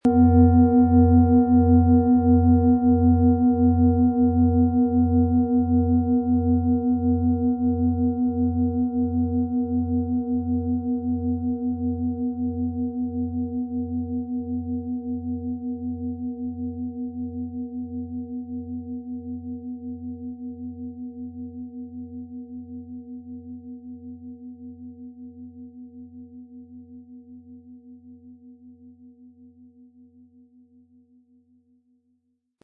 • Einsatzbereich: Über dem Kopf sehr intensiv spürbar. Ein unpersönlicher Ton.
• Mittlerer Ton: Mond
PlanetentönePlatonisches Jahr & Mond
MaterialBronze